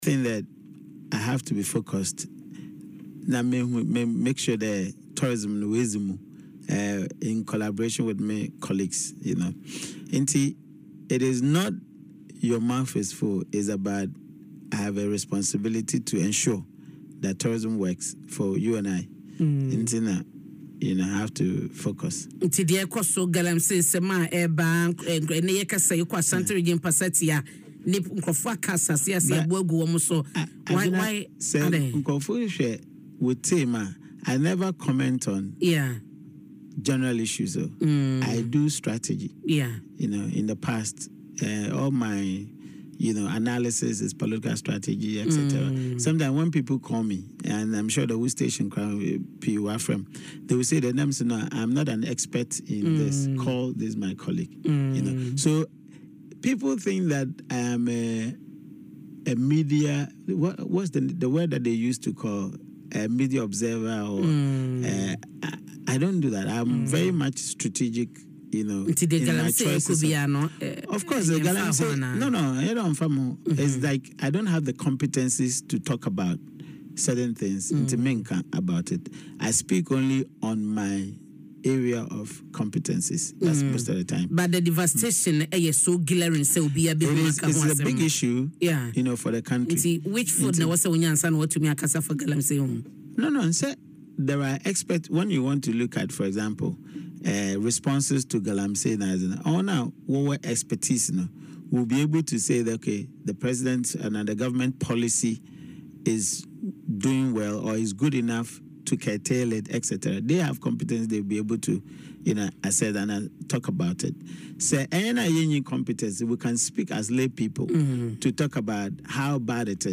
In an interview on Adom FM’s Dwaso Nsem morning show, Professor Mensah explained that he has drastically reduced his participation on social media to ensure he can concentrates on his responsibilities, particularly in advancing the tourism sector.